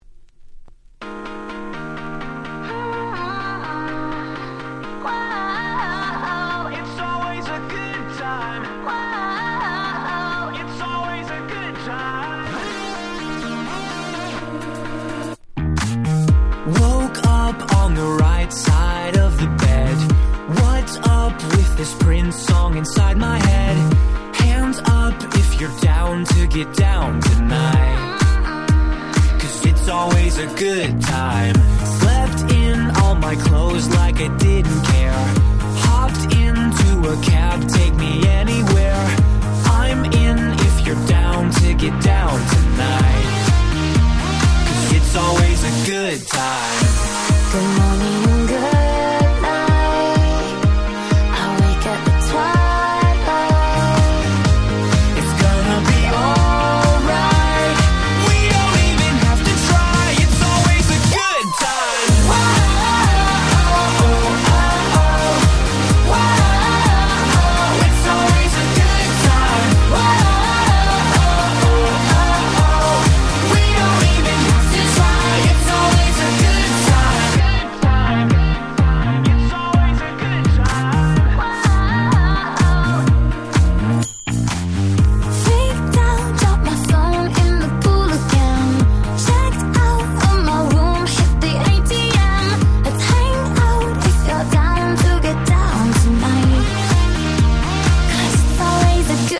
12' Super Hit R&B !!!!!